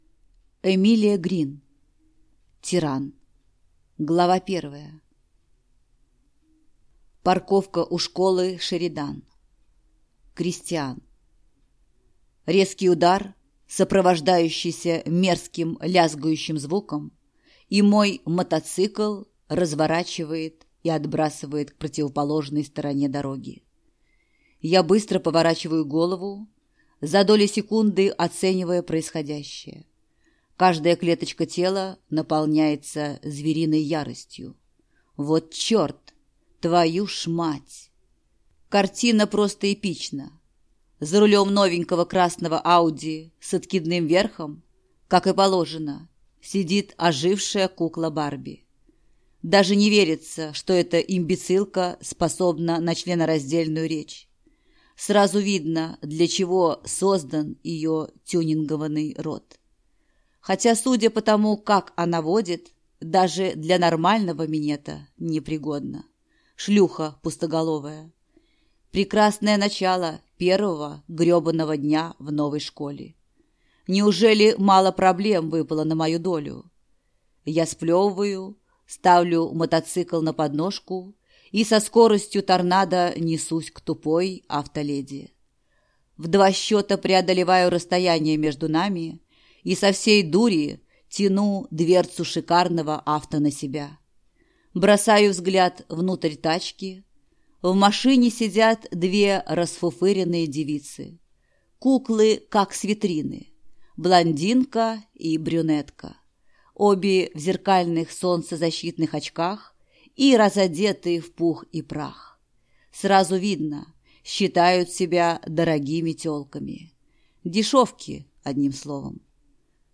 Аудиокнига Тиран | Библиотека аудиокниг